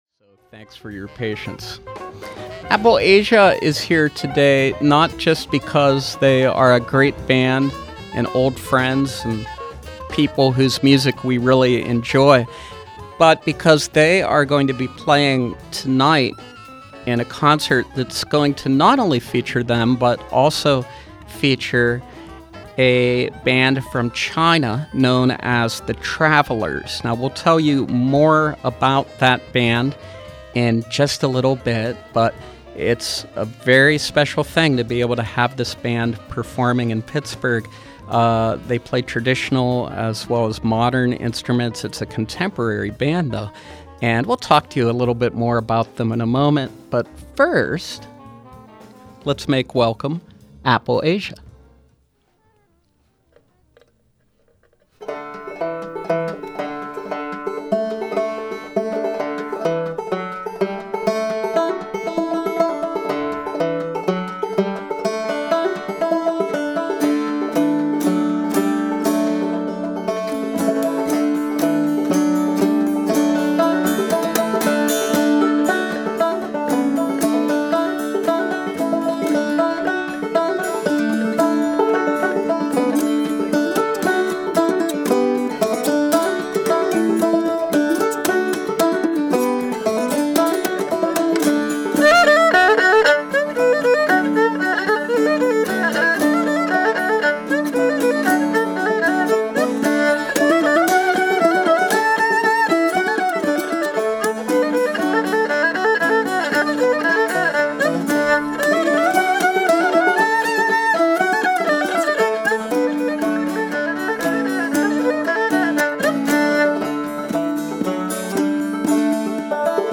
mountain dulcimer, banjo
erhu) traditional instruments.